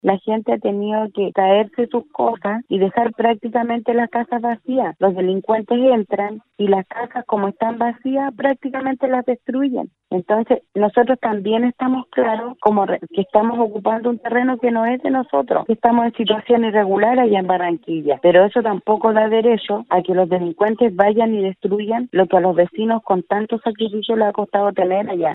En este lugar, producto de las restricciones de traslado al borde costero, se han incrementado de manera preocupante los robos.  Una vecina del sector, nos entregó su testimonio al respecto: